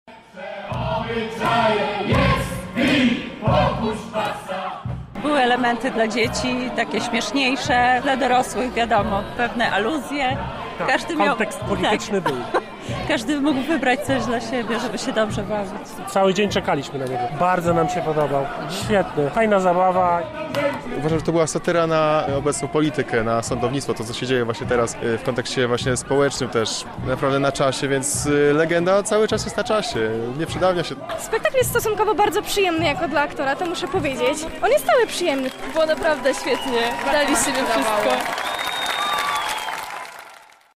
Dziesiątki osób zebrało się przed Trybunałem Koronnym, aby przenieść się do legendarnego świata.